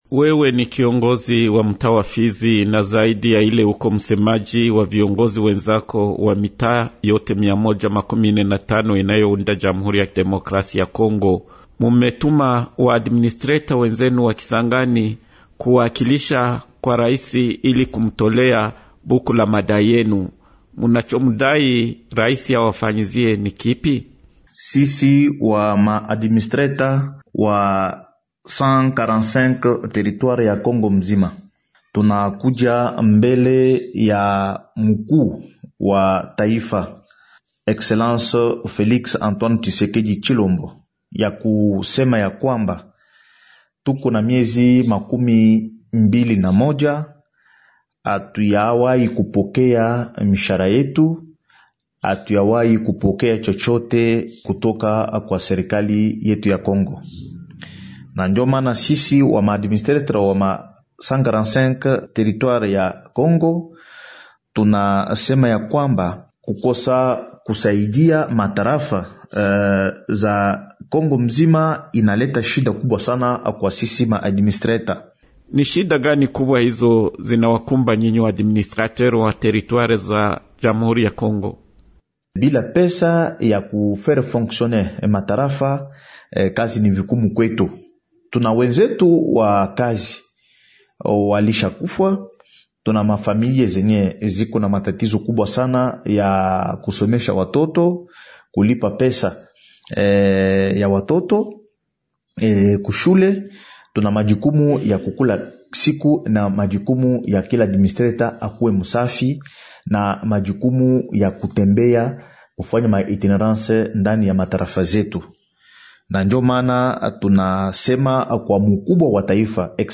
Mgeni ni Samy Kalonji Badibanga, msimamizi wa tarafa la Fizi na msemaji wa wasimamizi wa tarafa mmia moja makumi ine na tano  ya DRC. Anazungumza kuhusu barua ya malalamiko ambayo walikwenda kuwasilisha kwa Mkuu wa Nchi wakati wa misheni yake pa Kisangani.